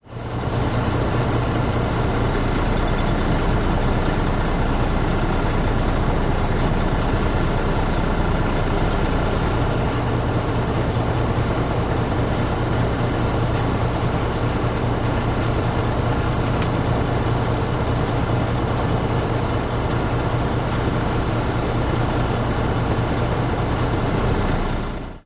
tr_macktruck_idle_02_hpx
Mack truck starts up and idles. Loop. Vehicles, Truck Idle, Truck Engine, Motor